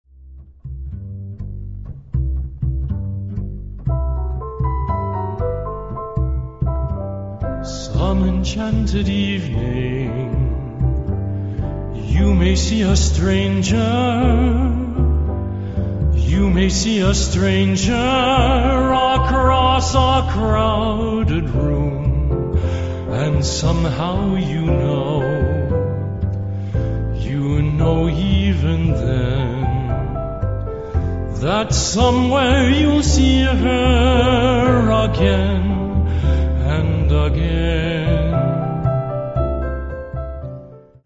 a gentle voice
baritone
light, velvety, and sweet without ever being cloying